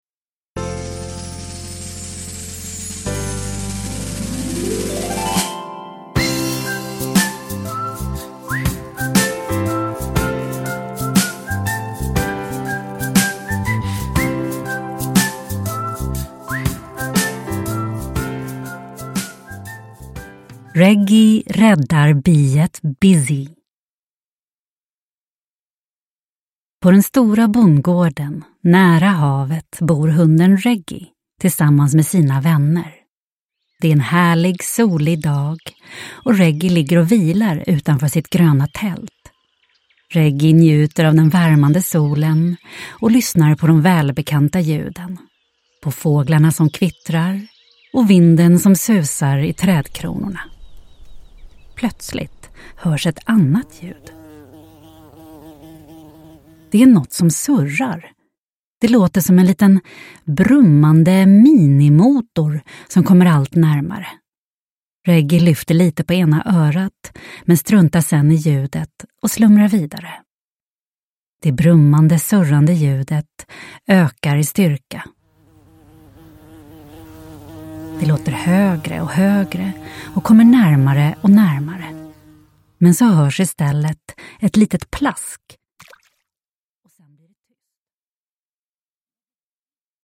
Reggy räddar biet Bizzi – Ljudbok – Laddas ner